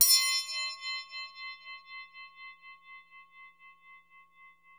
Index of /90_sSampleCDs/Roland LCDP03 Orchestral Perc/PRC_Orch Toys/PRC_Orch Triangl
PRC TREM.T0A.wav